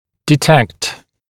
[dɪ’tekt][ди’тэкт]обнаруживать, определять, диагностировать, выявлять